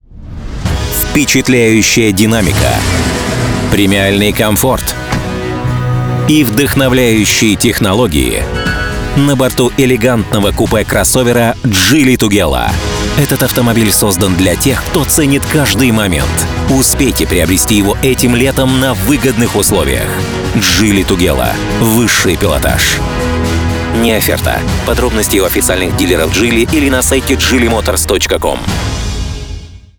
Примеры аудиороликов